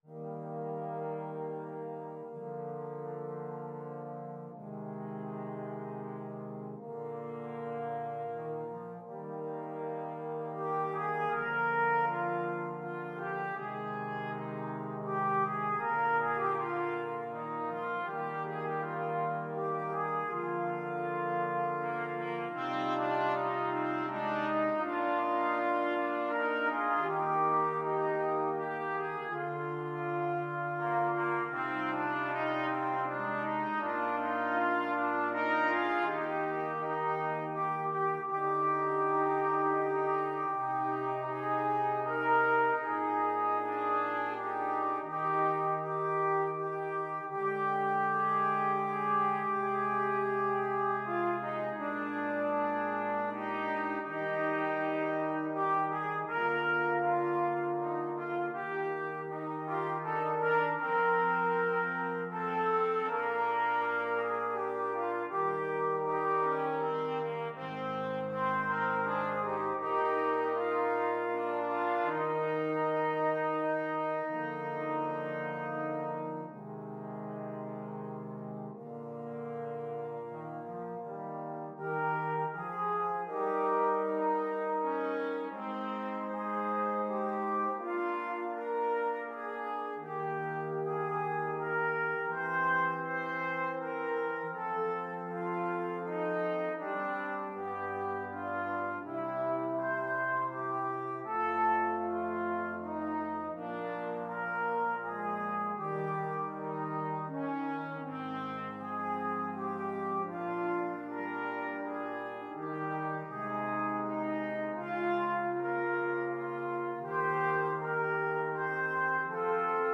Free Sheet music for Brass Quintet
Trumpet 1Trumpet 2French HornTrombone 1Trombone 2
3/4 (View more 3/4 Music)
Eb major (Sounding Pitch) (View more Eb major Music for Brass Quintet )
Andante
Classical (View more Classical Brass Quintet Music)